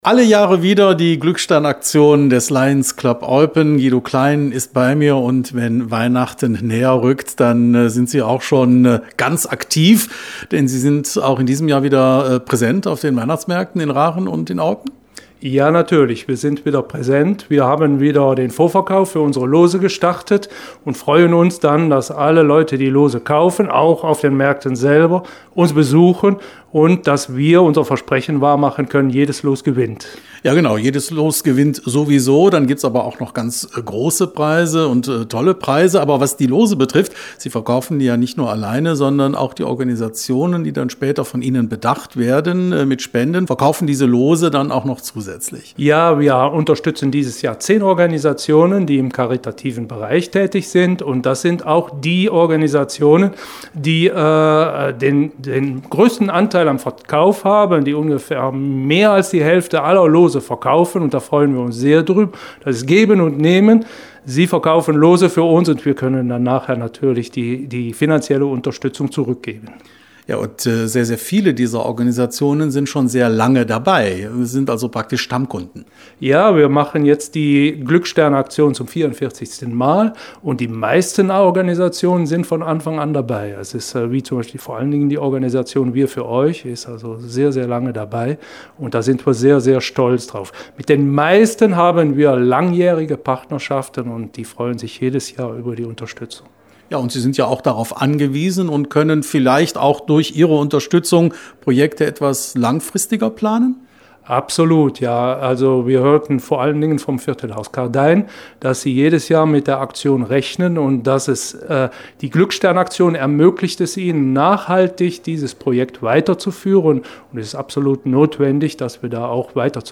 Radio Contact